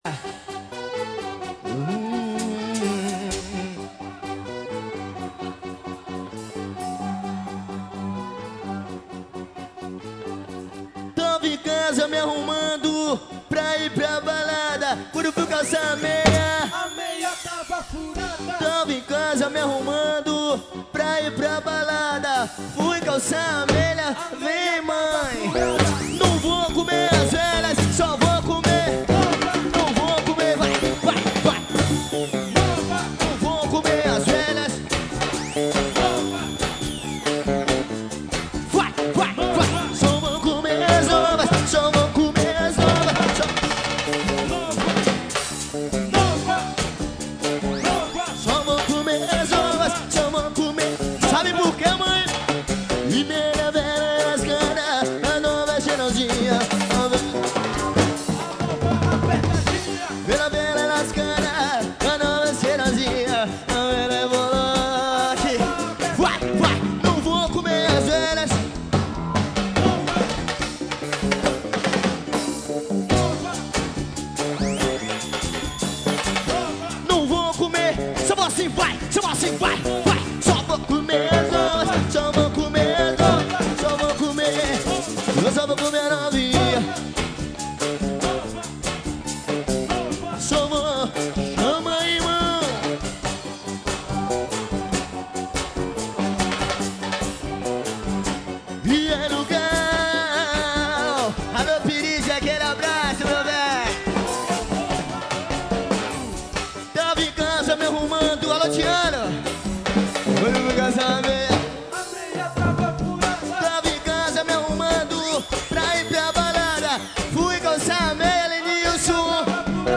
Pagodão.